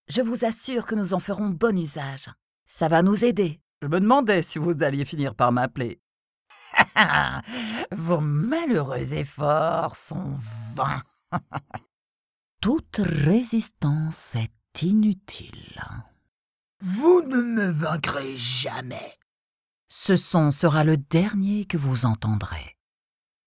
Warm, Diep, Vertrouwd, Volwassen, Zakelijk